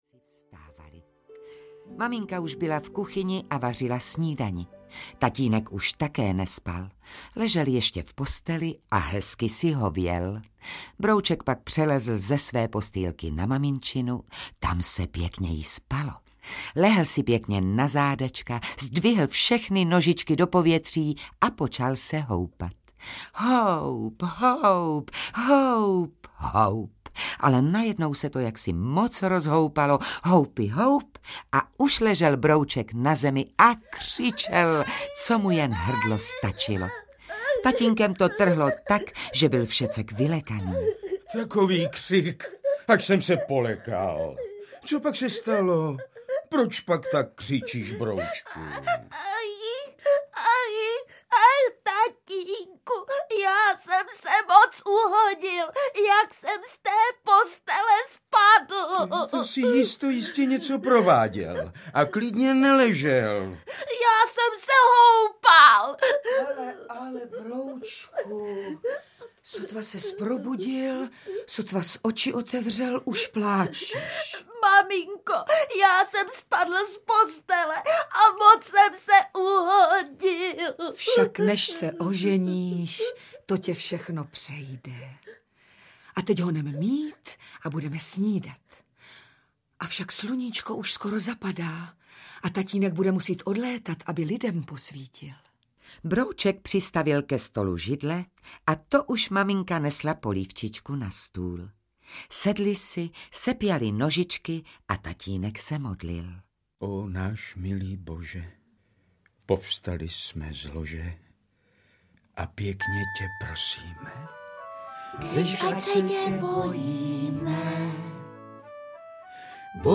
Broučci audiokniha
Pohádková dramatizace stejnojmenné knihy určená nejmladším posluchačům. Účinkují: Věra Galatíková, Jitka Molavcová, Petr Štěpánek, Eliška Balzerová a další.